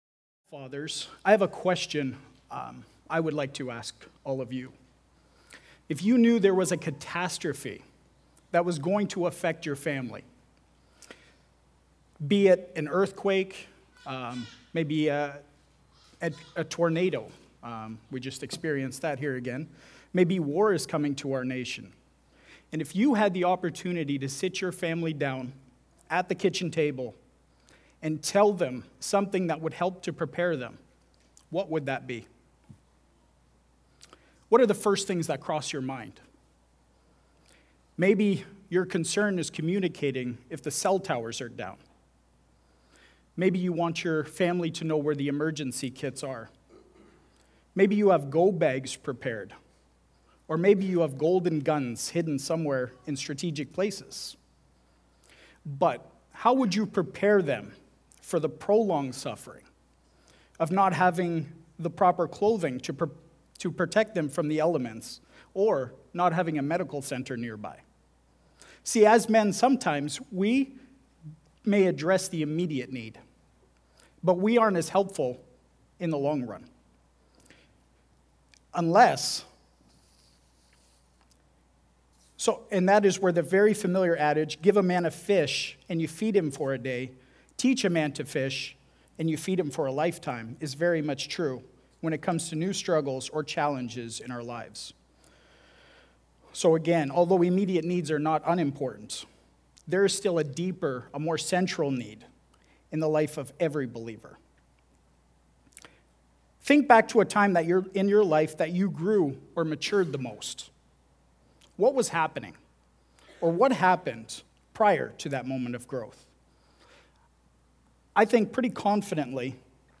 Unapologetic Preaching